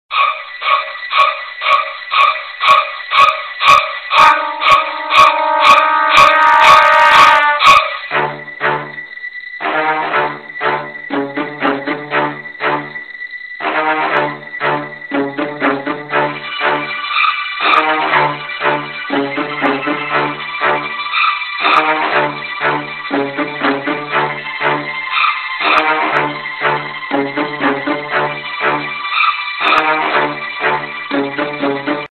Другие рингтоны по запросу: | Теги: Тревога, ужастик
Категория: Музыка из фильмов ужасов